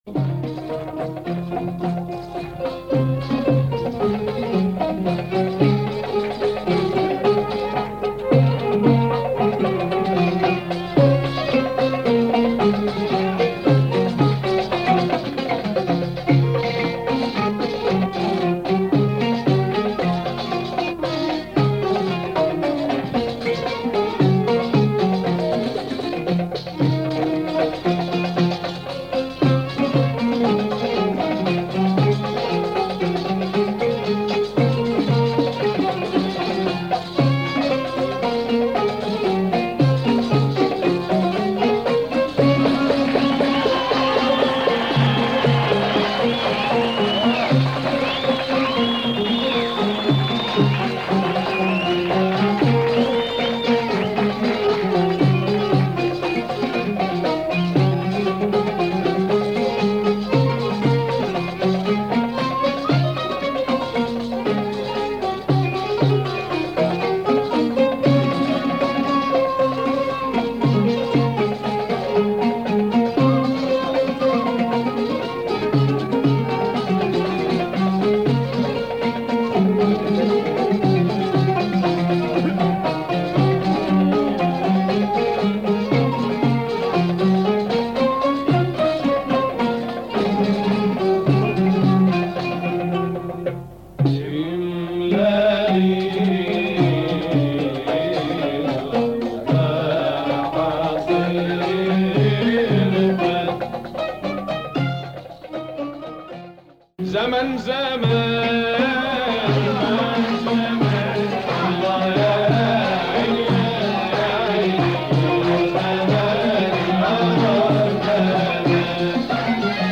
Some marks on the record, plays fine.
LP